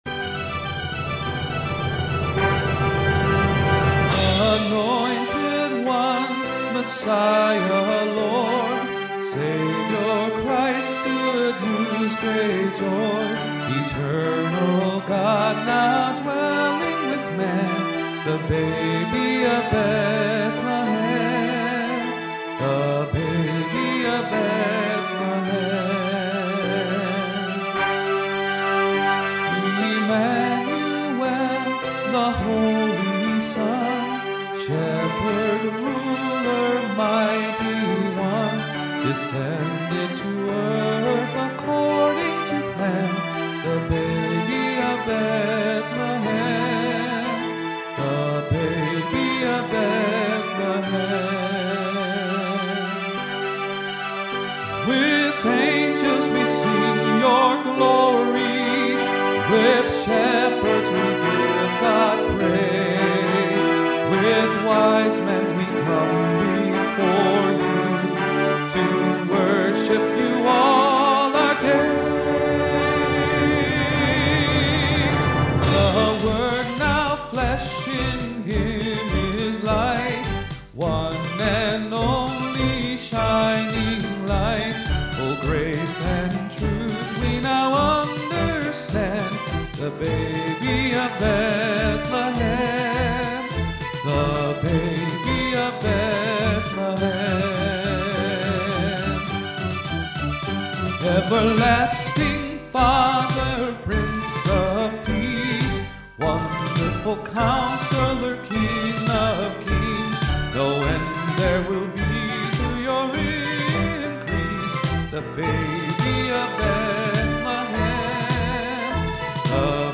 vocal/instrumental recordings